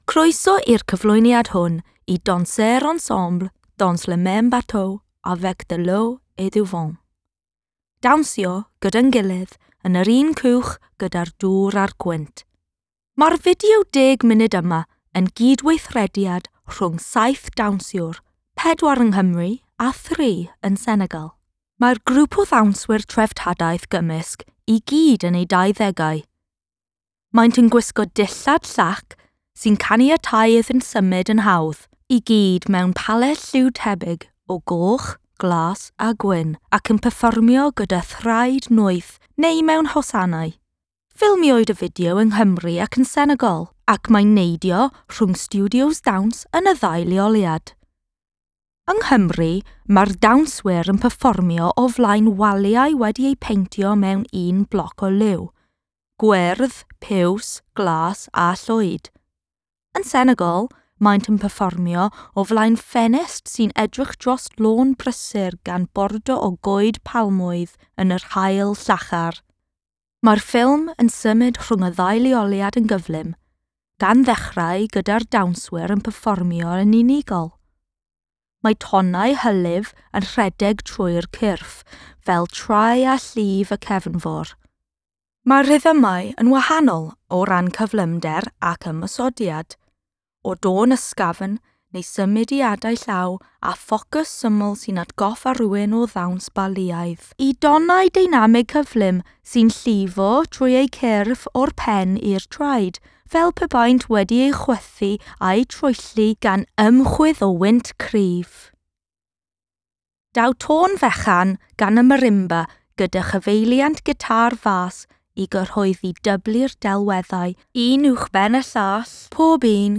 For an audio description of the film (produced by Sightlines Audio Description Services) use the following links: